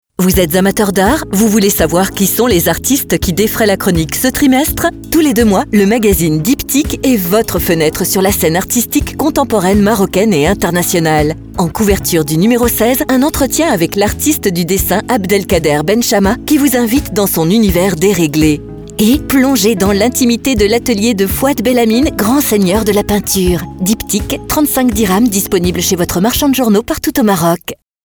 Pymprod réalise pour vous des spots publicitaires avec nos voix off masculines et féminines.